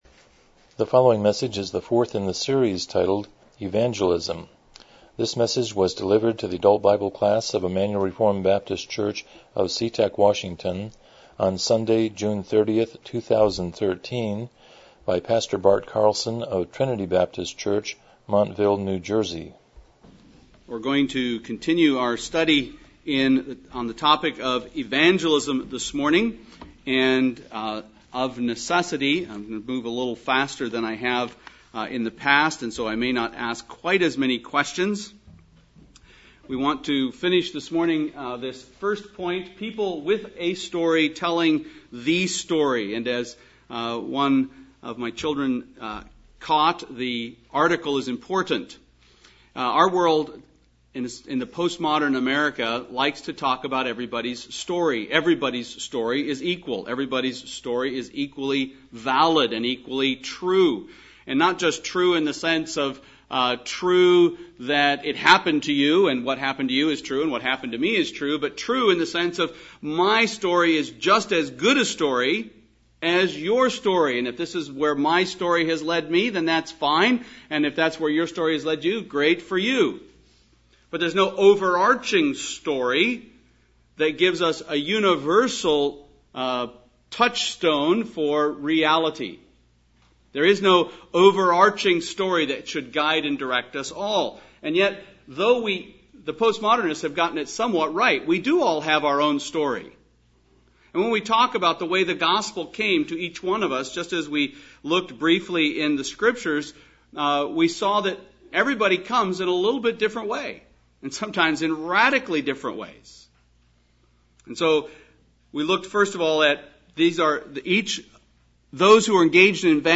Evangelism Service Type: Sunday School « 4 Avoiding Worldliness